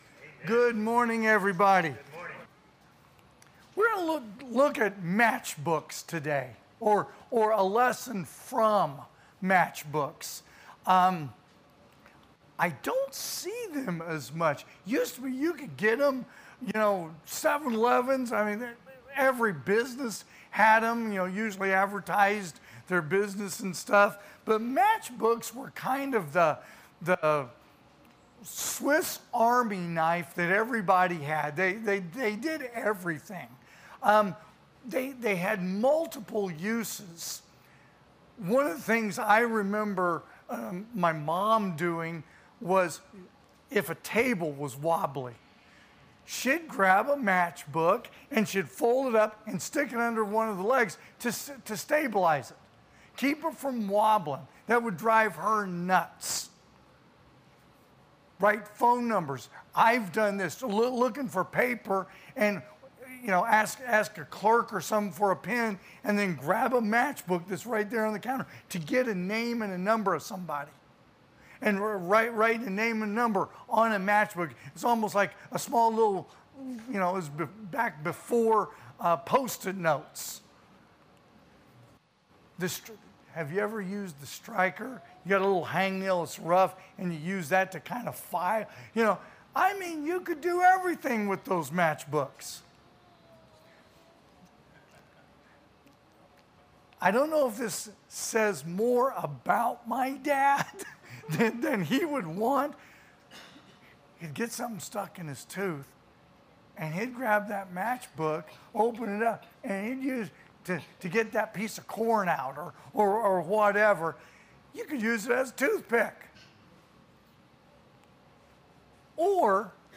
2025 (AM Worship) "Matchbooks"
Sermons